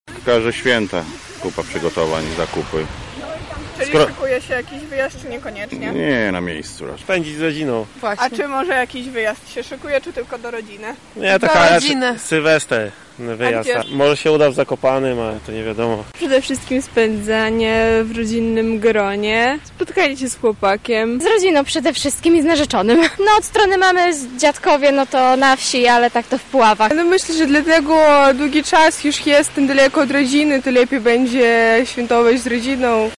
[SONDA] Gdzie lublinianie spędzą Boże Narodzenie?
SONDA: plany na święta